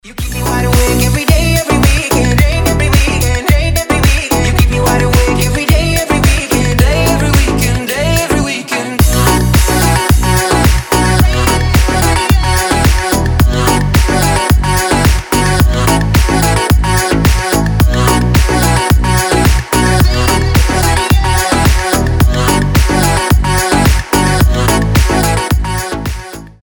мужской голос
заводные
dance
tropical house
ремиксы